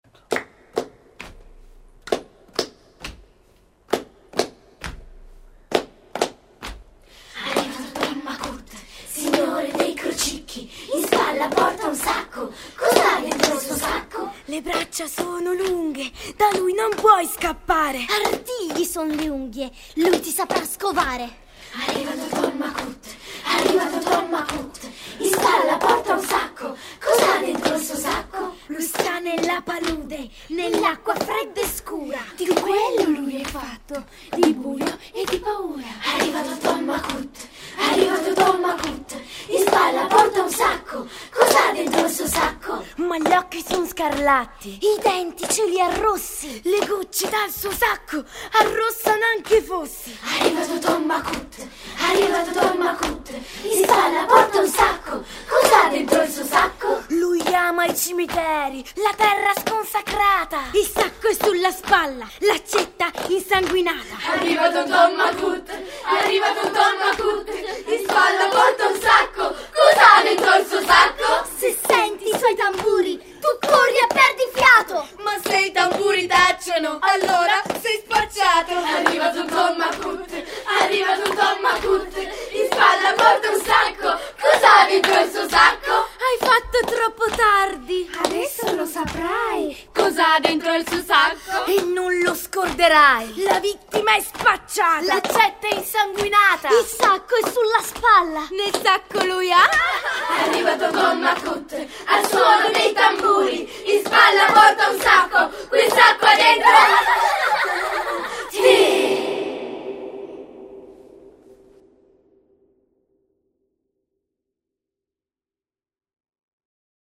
Ascolta l’agghiacciante filastrocca recitata dai bambini di Noreturn.